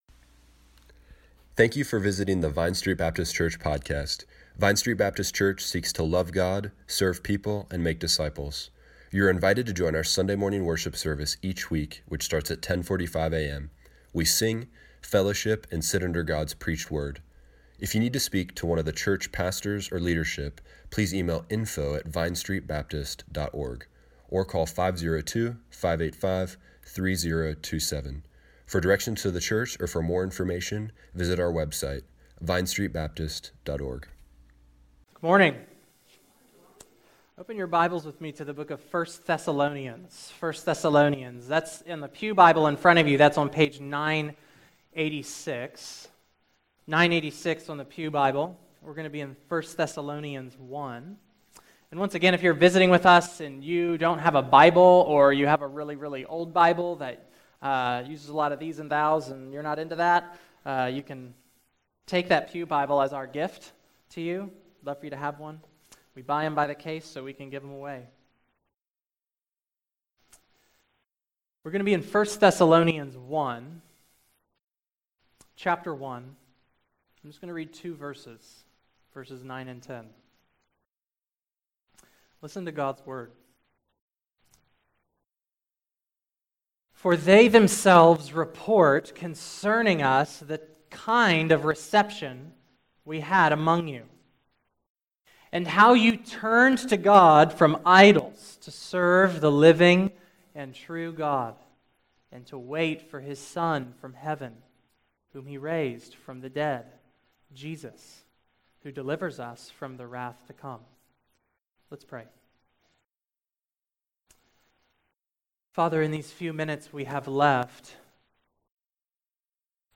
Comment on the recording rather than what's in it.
January 28, 2018 Morning Worship | Vine Street Baptist Church